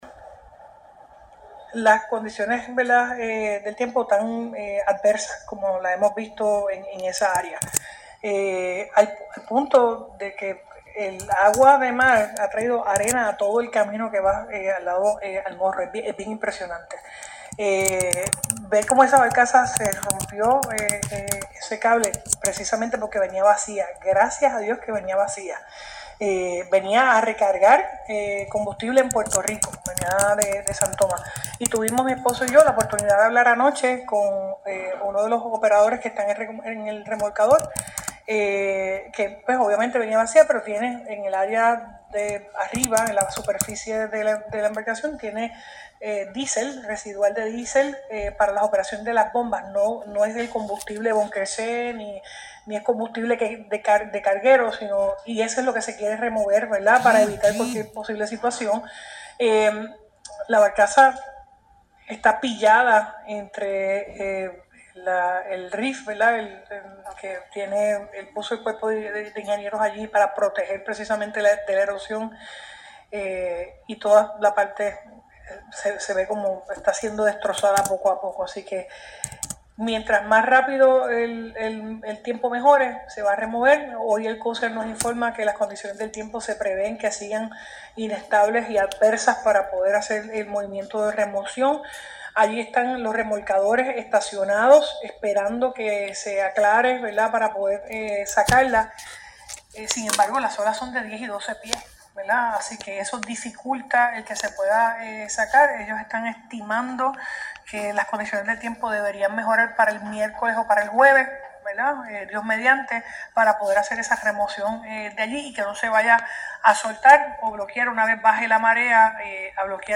Mientras mas pronto mejoren las condiciones marítimas, mas rápido se podrá remover la barcaza que quedó encallada en la periferia del Castillo San Felipe del Morro. Así lo dijo la gobernadora Jenniffer González quien aseguró que la nave no transportaba combustible al momento del accidente, salvo el diesel residual que se mantiene en la embarcación para su operación.
GOBERNADORA-BARCAZA.mp3